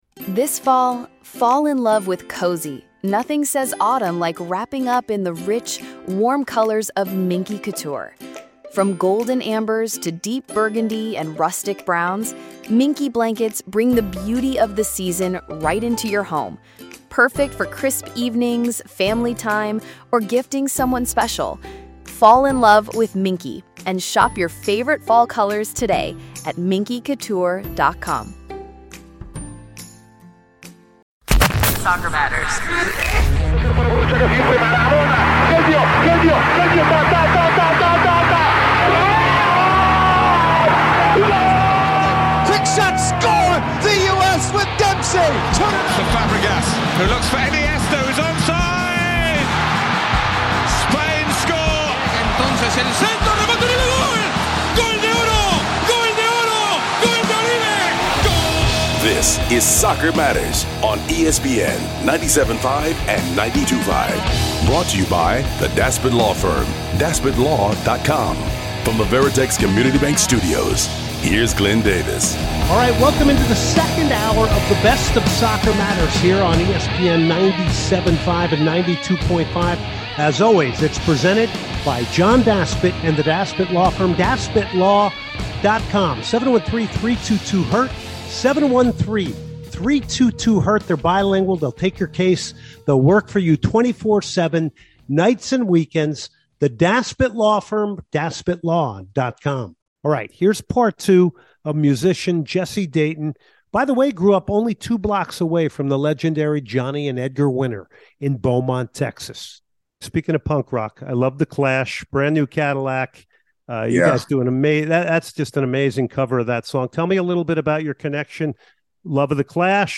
interview
conversation